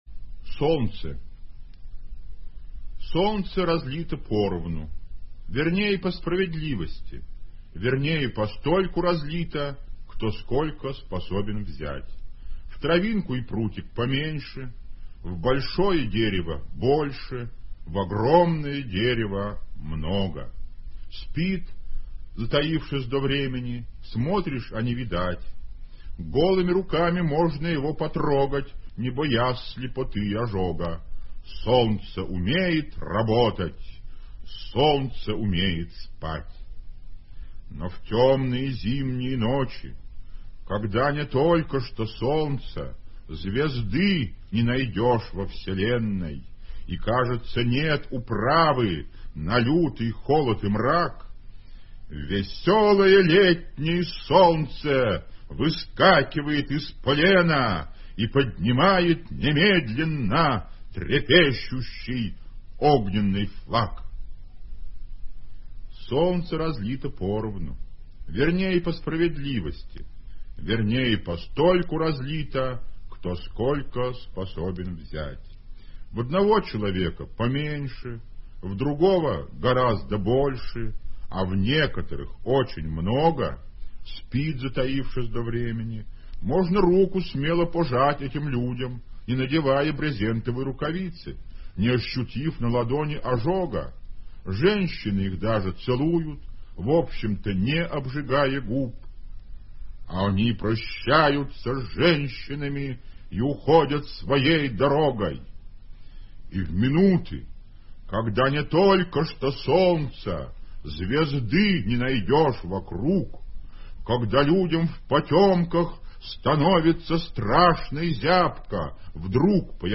1. «Владимир Солоухин – Солнце (читает автор)» /
Solouhin-Solnce-chitaet-avtor-stih-club-ru.mp3